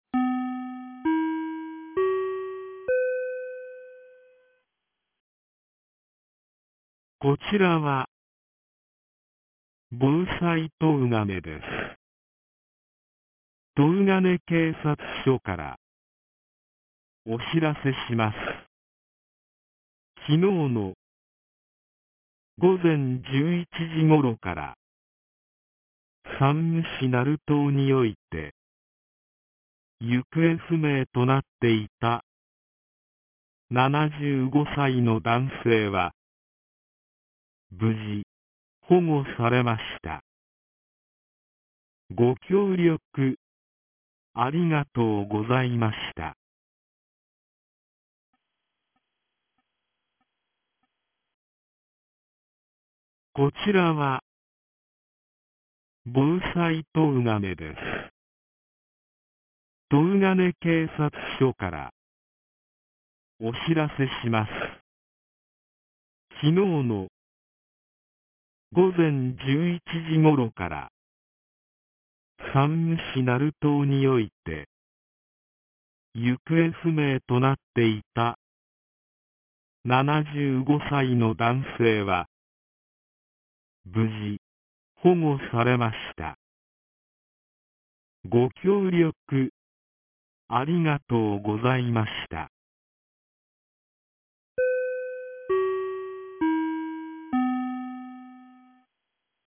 2023年12月14日 09時32分に、東金市より防災行政無線の放送を行いました。